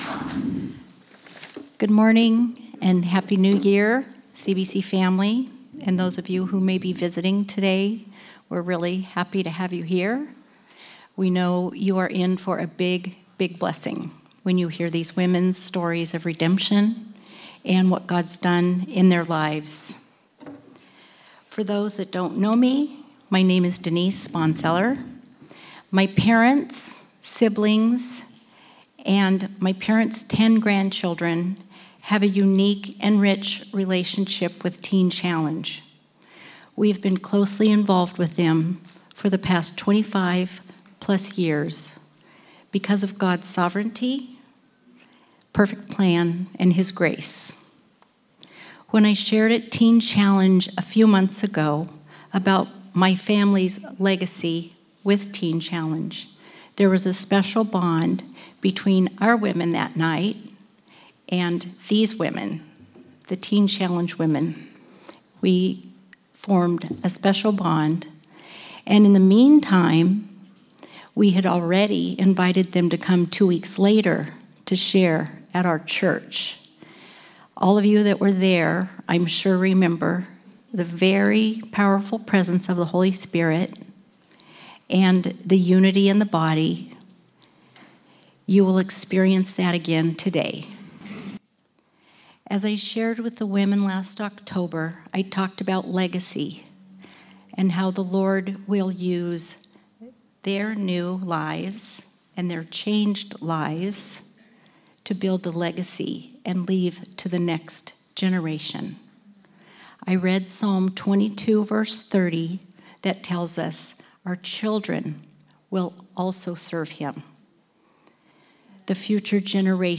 This Sunday Teen Challenge will be leading our Sunday morning service.
The women’s stories of redemption are powerful and moving and we cannot wait to welcome them this Sunday.